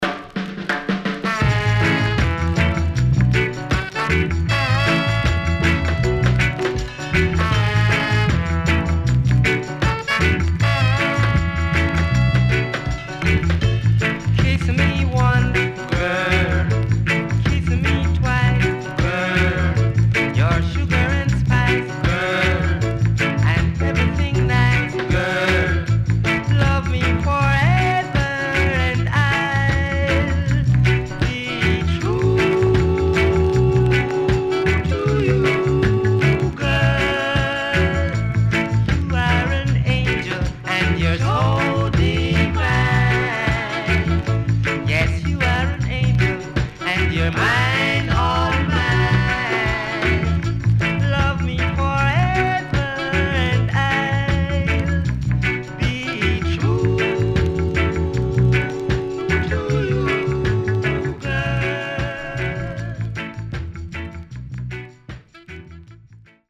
CONDITION SIDE A:VG(OK)
SIDE A:全体的にかるいチリノイズがあり、少しプチノイズ入ります。